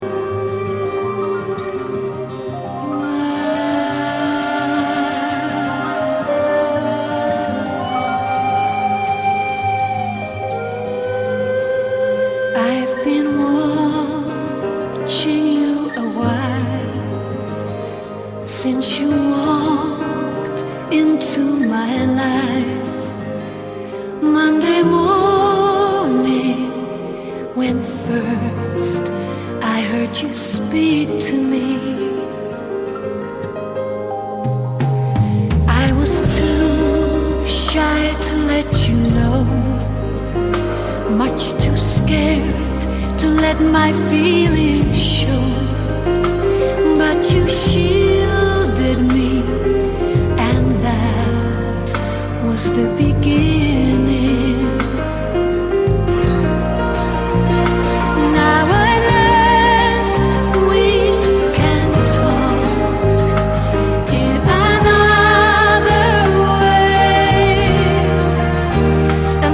TGS trailer song sample